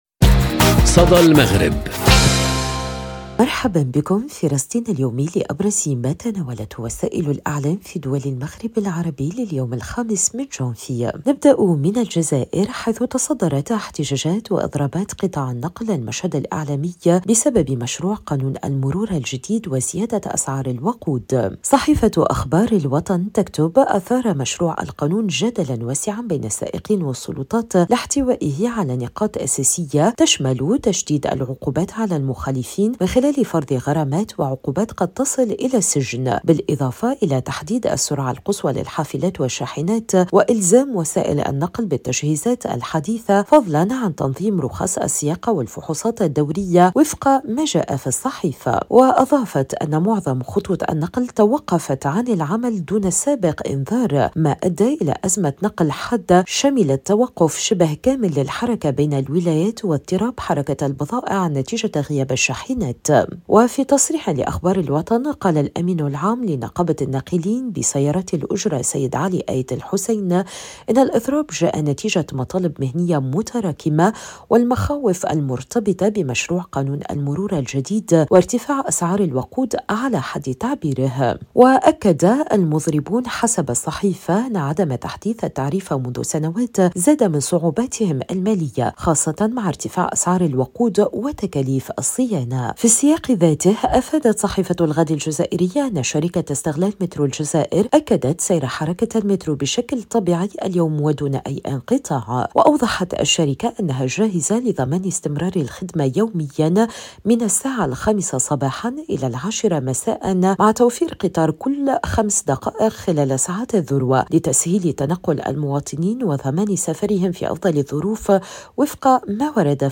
صدى المغرب برنامج إذاعي يومي يُبث عبر راديو أوريان إذاعة الشرق، يسلّط الضوء على أبرز ما تناولته وسائل الإعلام في دول المغرب العربي، بما في ذلك الصحف، القنوات التلفزية، والميديا الرقمية.